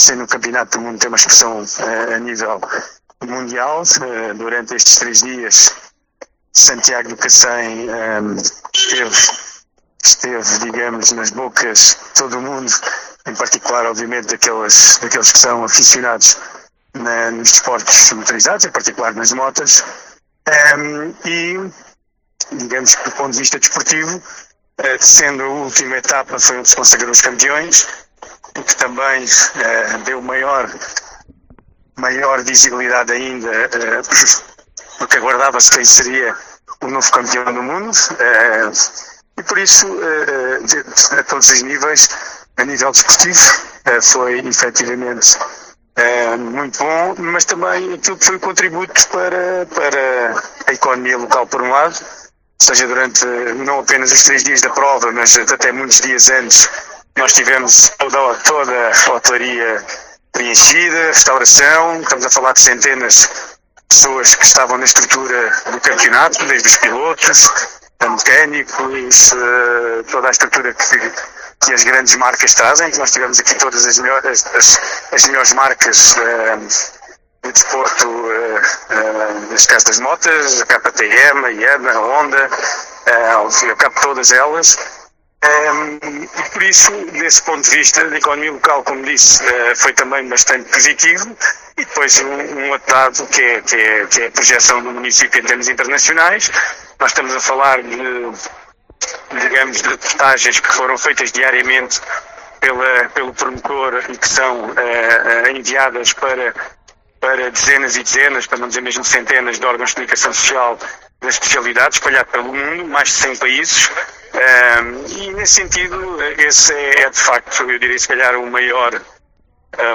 Declarações do Presidente da Câmara Municipal de Santiago do Cacém, Álvaro Beijinha